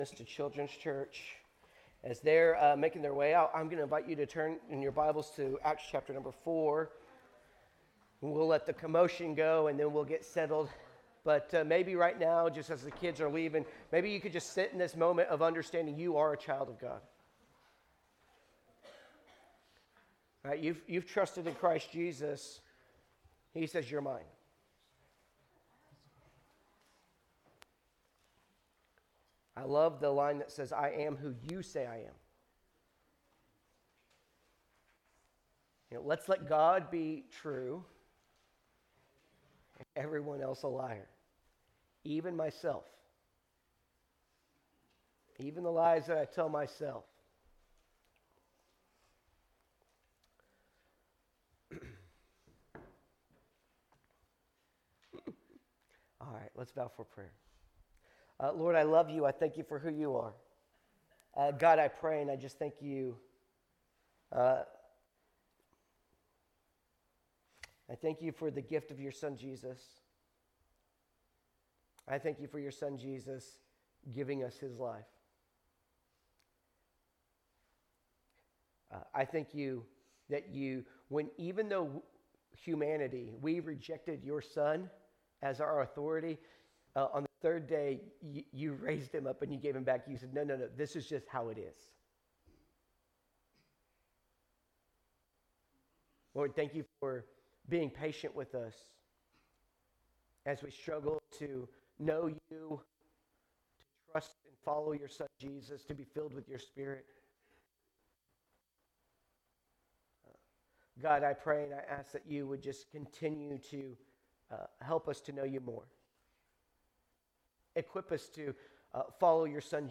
Sermons by Friendswood Baptist Church